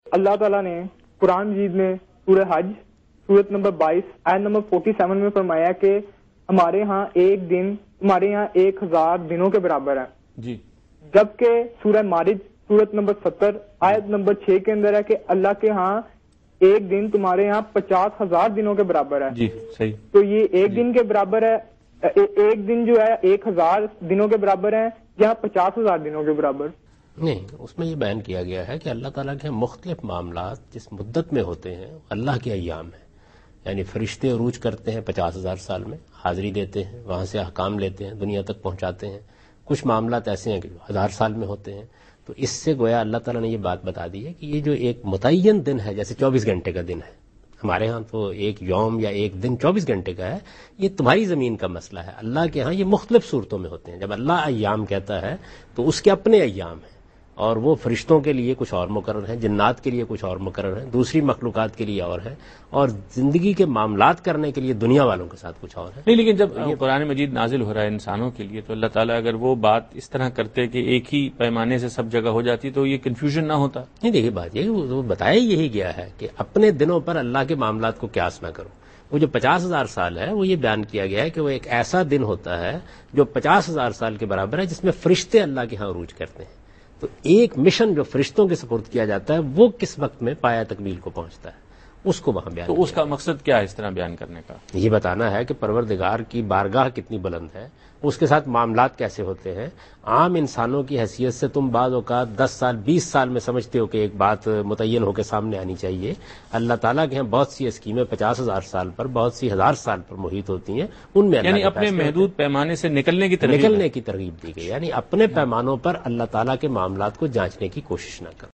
Javed Ahmad Ghamidi answers a question about "Contradiction in Quran about Length of Day" in program Deen o Daanish on Dunya News.
جاوید احمد غامدی دنیا نیوز کے پروگرام دین و دانش میں اس غلط فہمی کہ قرآن میں دن کی لمبائی سے متعلق اختلاف ہے کا جواب دے رہے ہیں۔